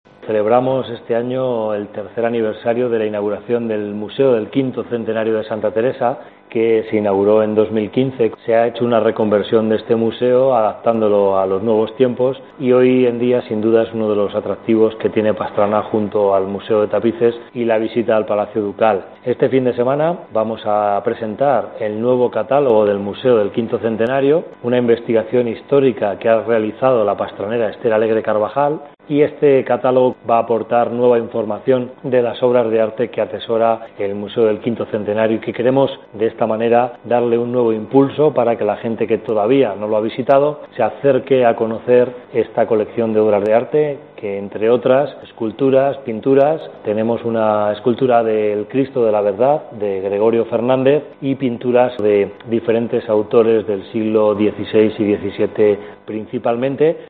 La Fiesta patronal de este año tiene un foco principal, como así nos lo indica el Alcalde pastranero, José Ignacio Ranera.